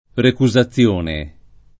recusazione [ reku @ a ZZL1 ne ]